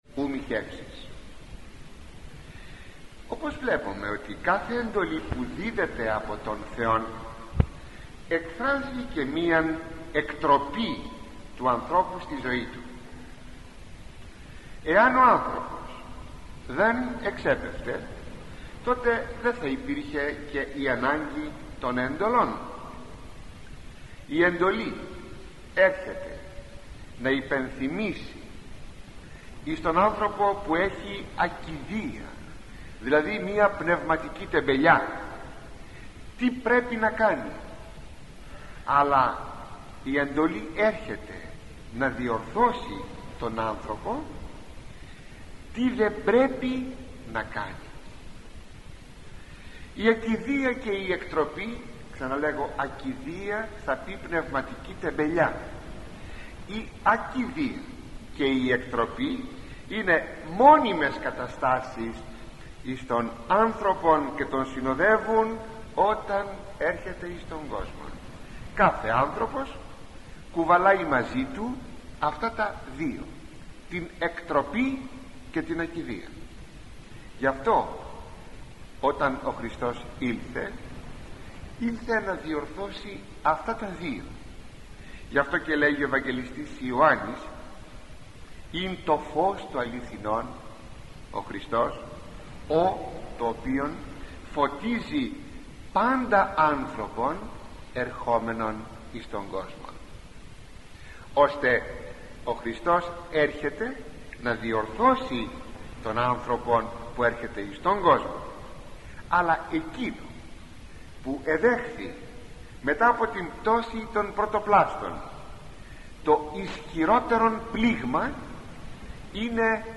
Η έκτη Εντολή: “Ου μοιχεύσεις” – ηχογραφημένη ομιλία του Μακαριστού Αρχιμ.